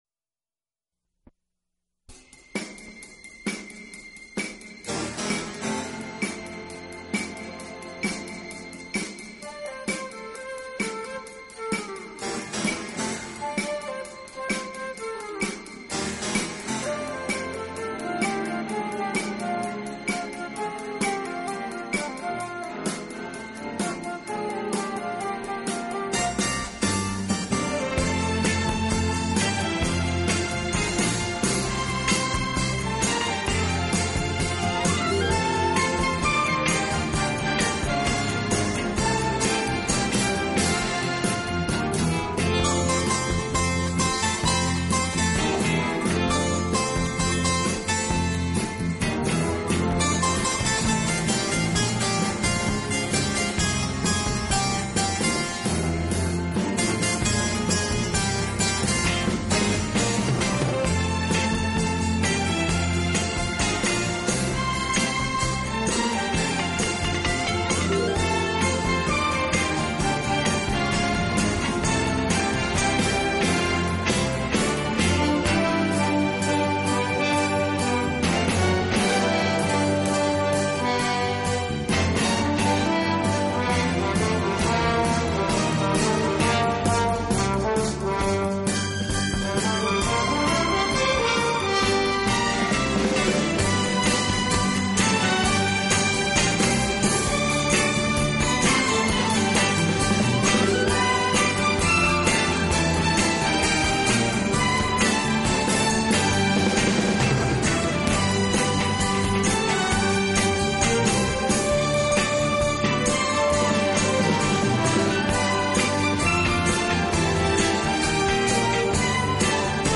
【轻音乐】
音乐类型：Pop